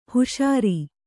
♪ huṣāri